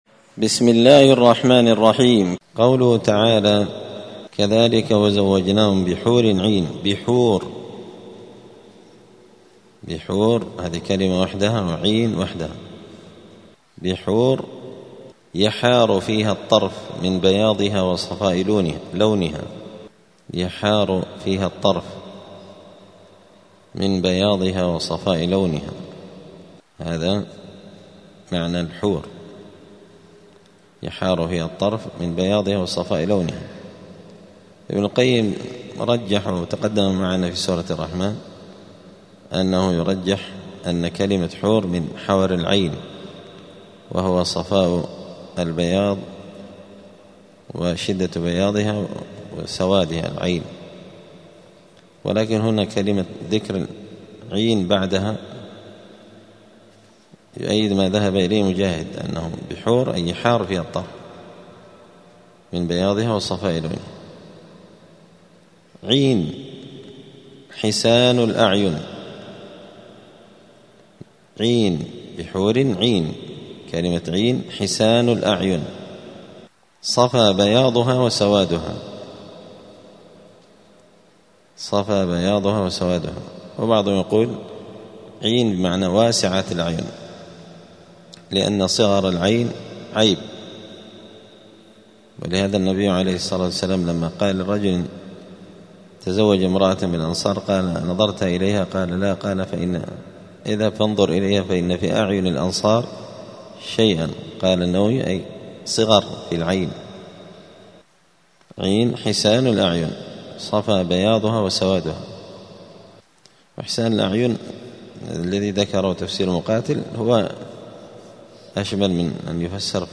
248الدرس-الثامن-والأربعون-بعد-المائتين-من-كتاب-زبدة-الأقوال-في-غريب-كلام-المتعال.mp3